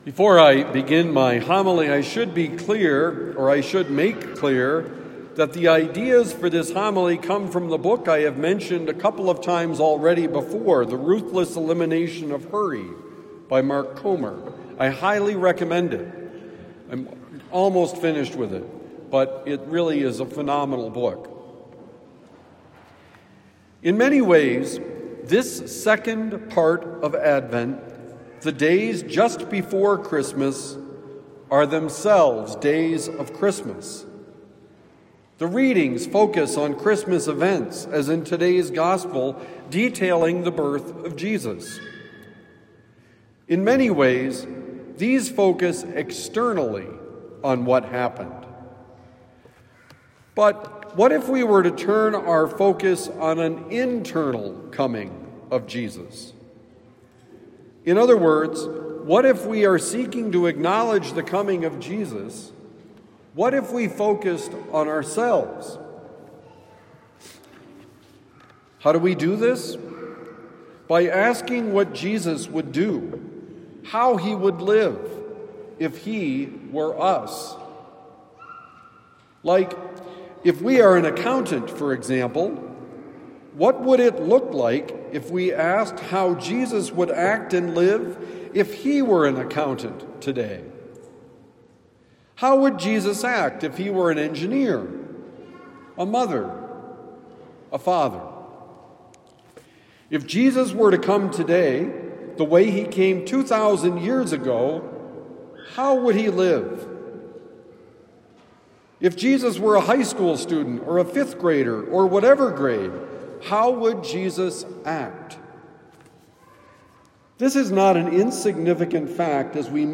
The Lifestyle of Jesus: Homily for Sunday, December 21, 2025